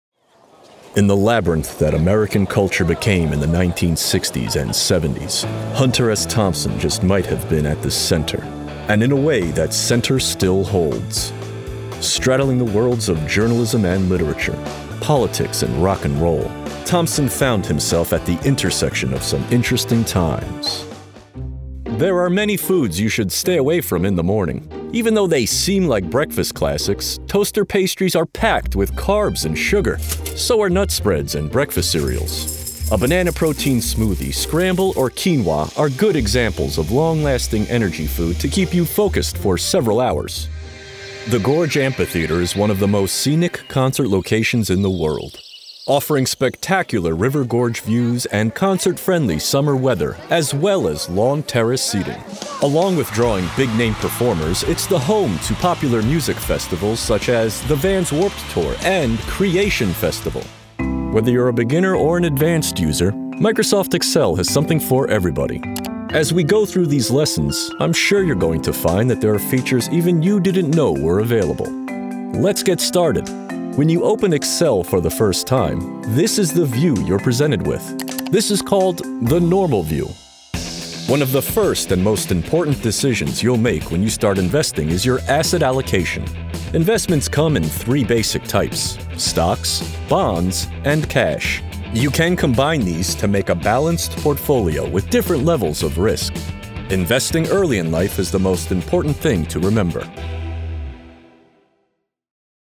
North American (General), North American (US General American), North American (US New York, New Jersey, Bronx, Brooklyn)
Young Adult
Middle Aged
My voice can be helpful, friendly, fun, cool, and rugged.